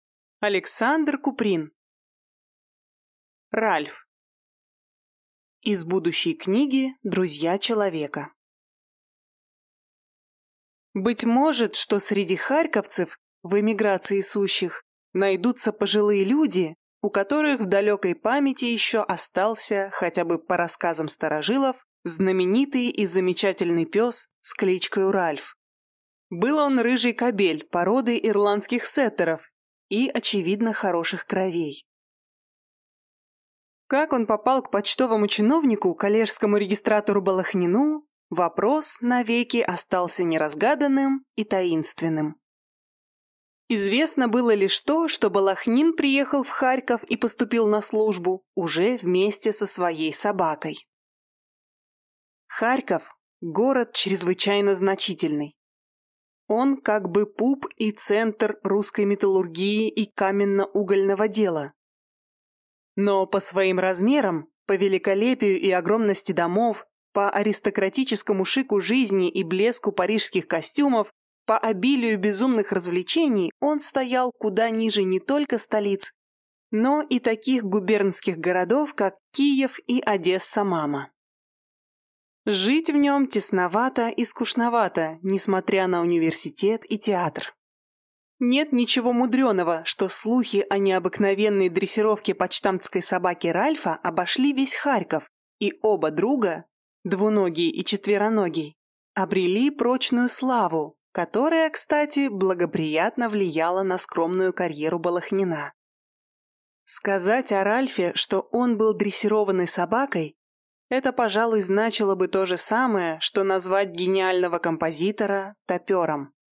Аудиокнига Ральф | Библиотека аудиокниг